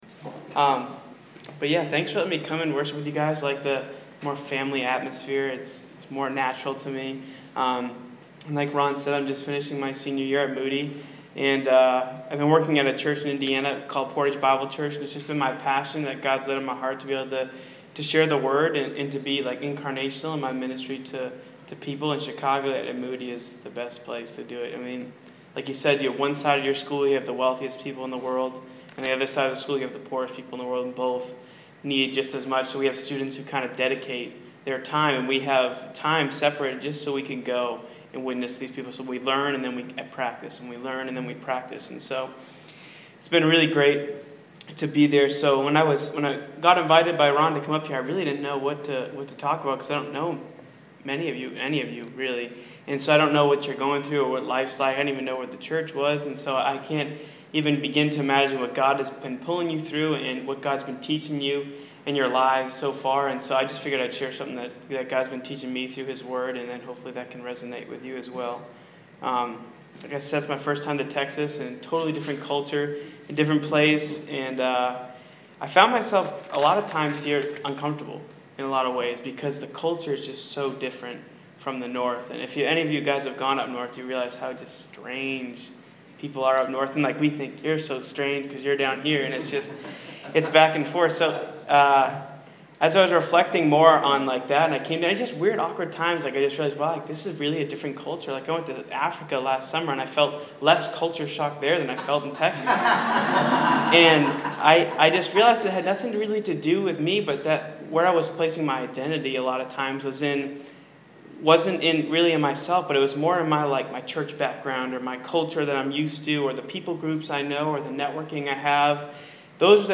Sermons